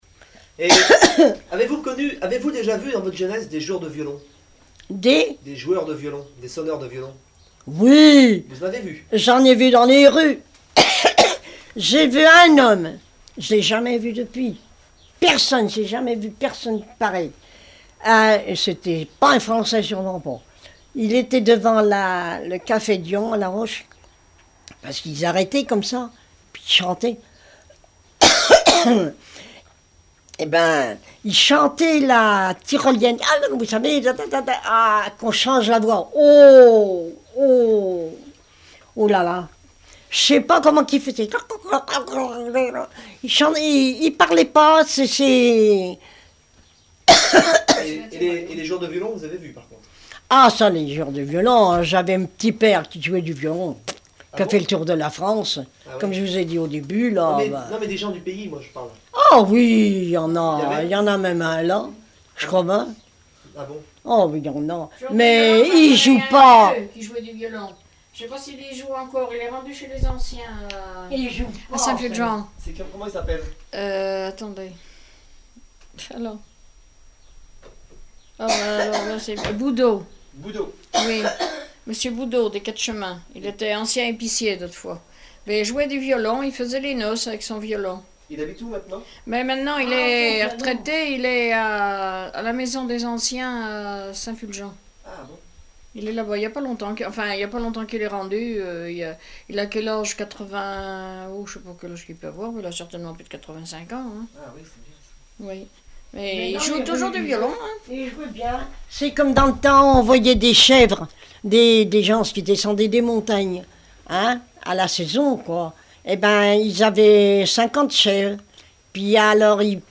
Mémoires et Patrimoines vivants - RaddO est une base de données d'archives iconographiques et sonores.
témoigneges et chansons populaires
Témoignage